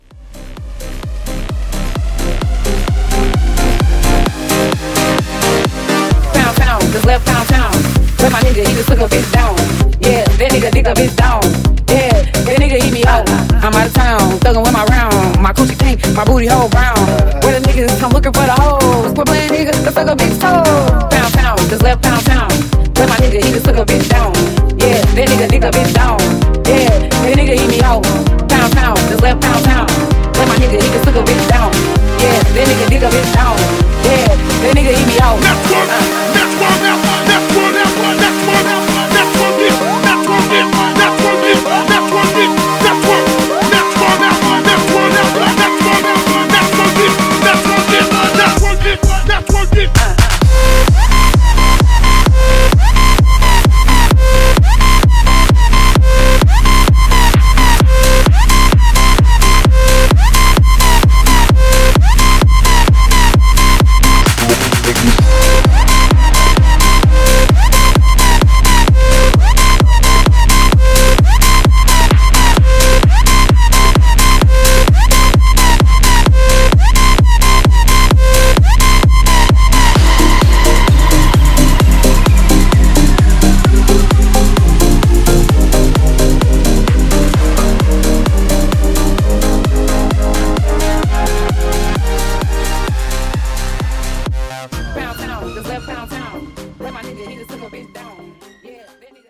Genre: 80's
Clean BPM: 109 Time